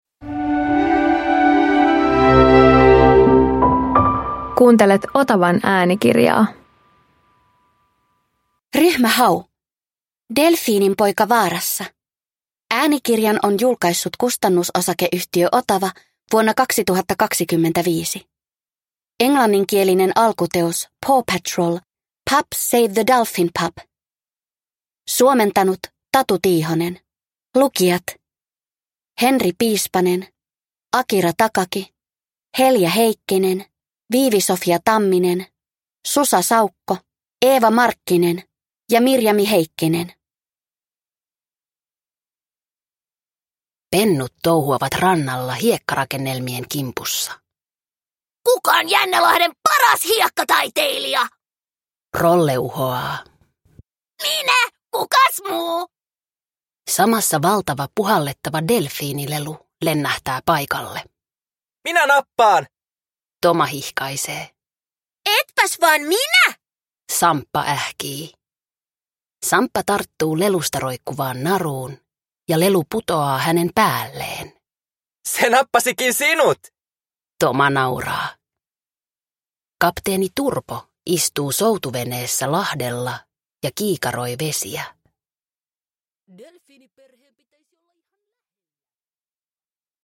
Ryhmä Hau - Delfiininpoika vaarassa – Ljudbok